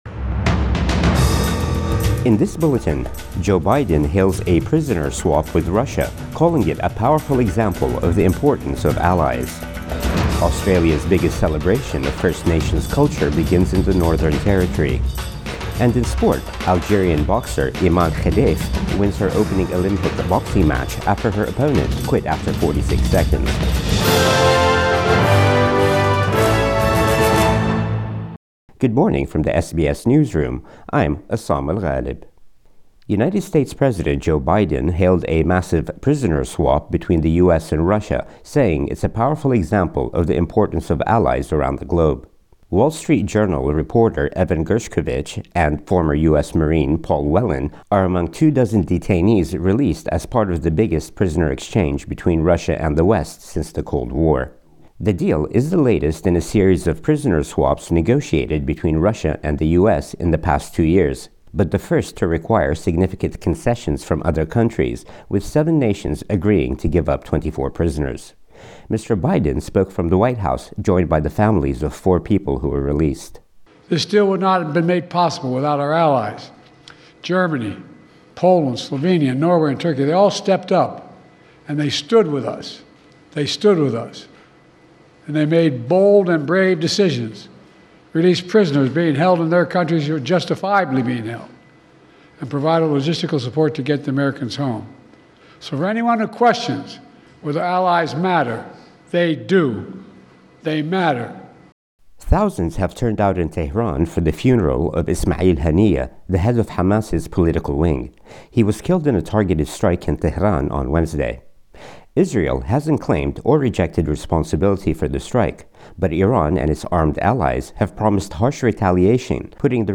Midday News Bulletin 2 August 2024